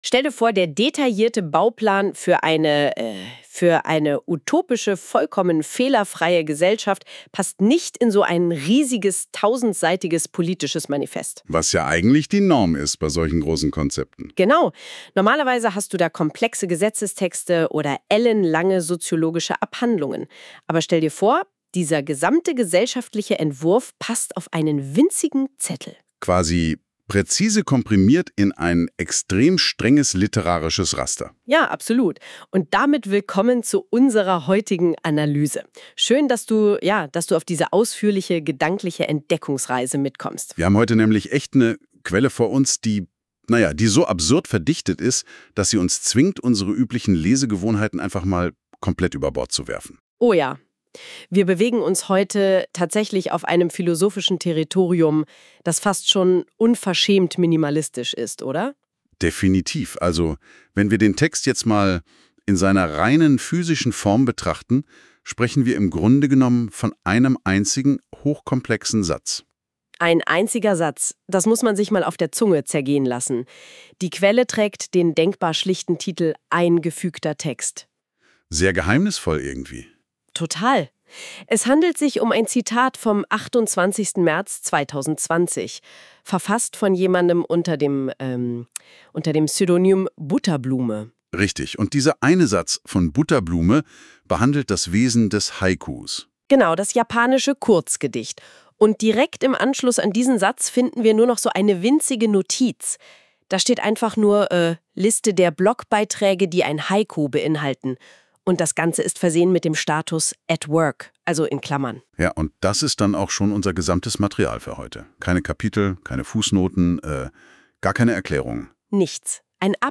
Dieser Studien-Text wurde für Ihr Bewusstsein von der KI meta-hermeneutisch zum Zweck einer tiefergehenden Analyse rezensierend, moderierend und lektorierend für das sozial-plastische Gespräch aufbereitet und aus poetologisch praktischen Gründen von der Instanz des erotischen Strebens Faust ungefiltert wiedergegeben!